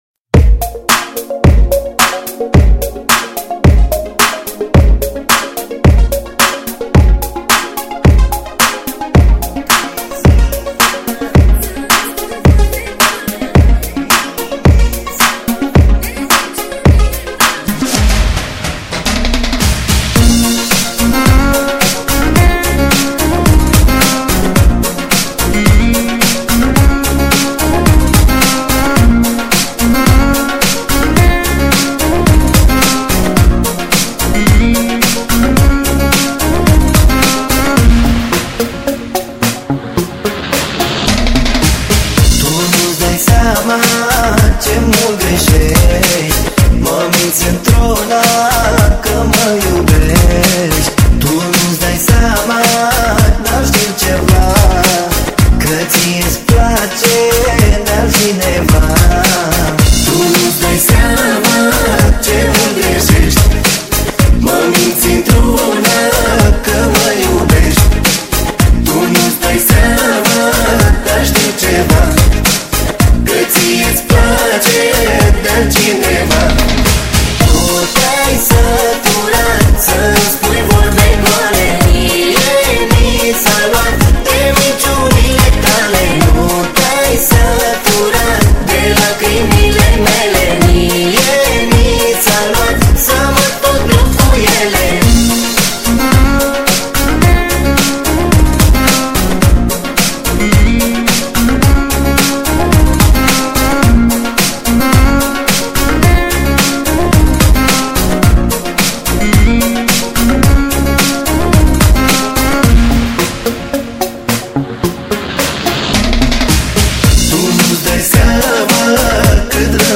强烈HOUSE节奏与翻滚，PROGRESSIVE音色，超魅力登场\迷情在今夜\随心摇摆的激情嗨舞热碟,
为低音质MP3